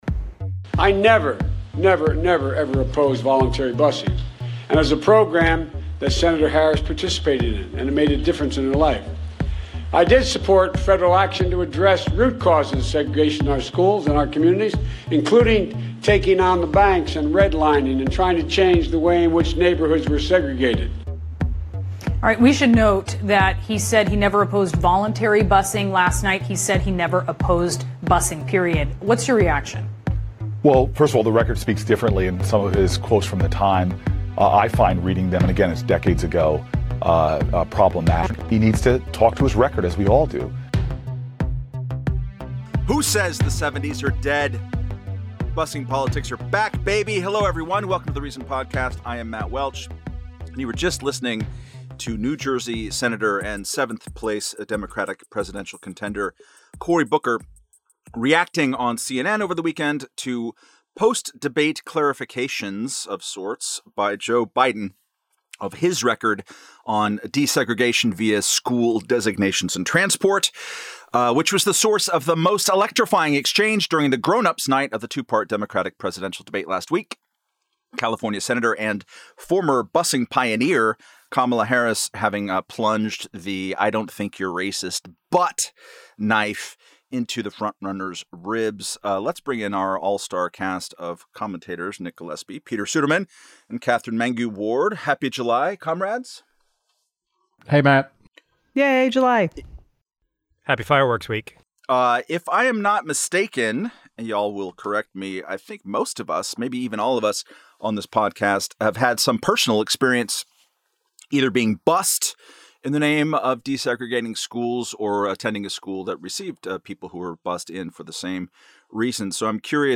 Editors Roundtable